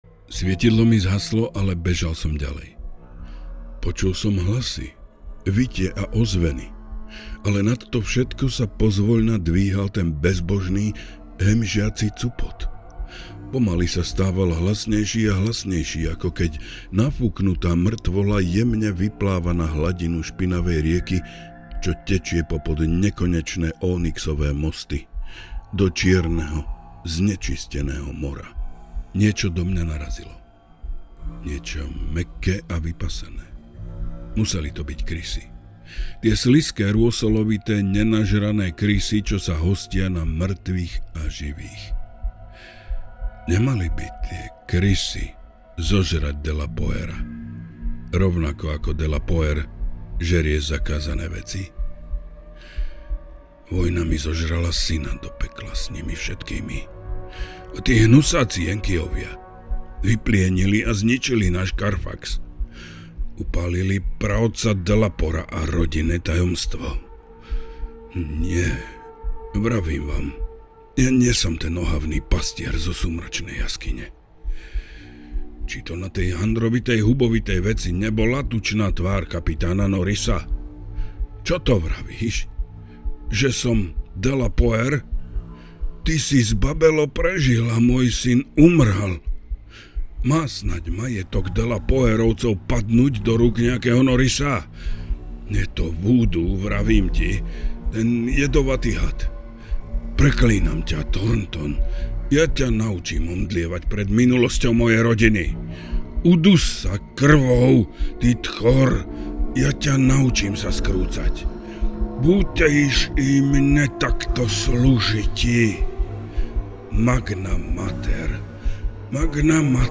Kniha má vďaka jeho emotívnemu hlasu tú správnu atmosféru. Taktiež kladne hodnotím jemný hudobný doprovod.